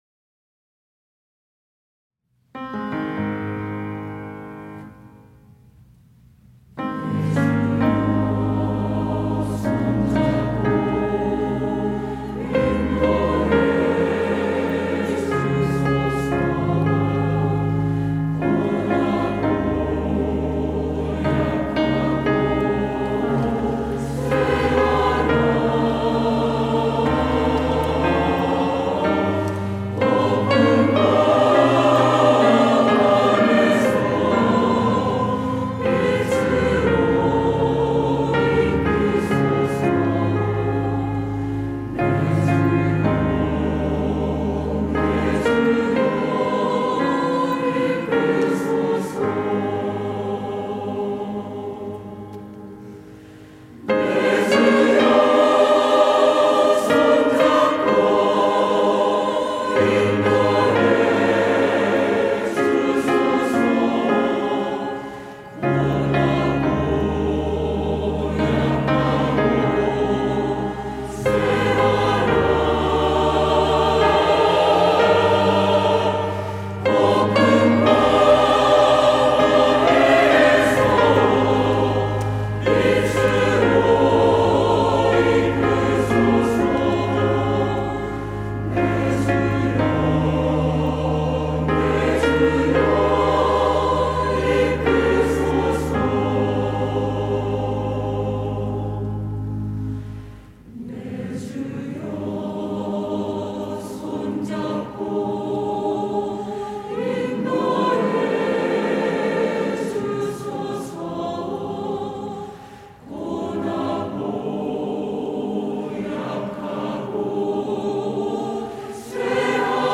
할렐루야(주일2부) - 귀하신 주여, 내 손 잡으소서
찬양대